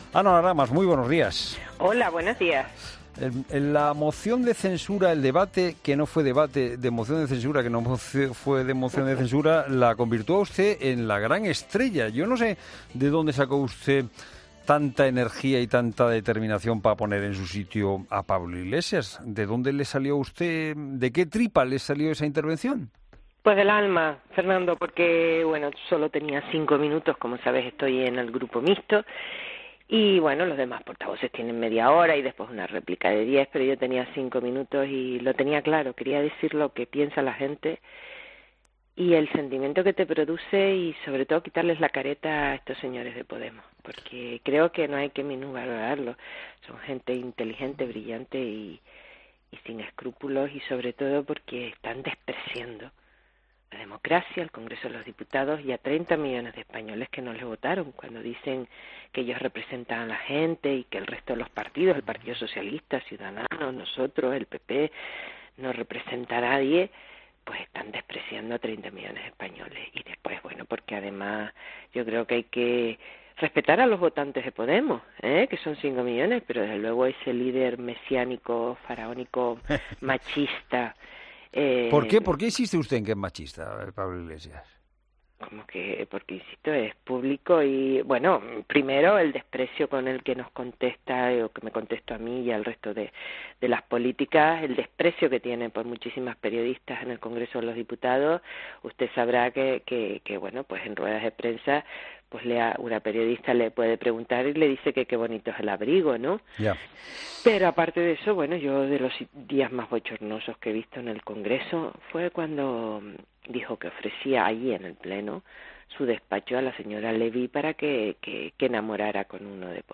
Ana Oramas, diputada de Coalición Canaria, en "La Mañana Fin de Semana"
Entrevista política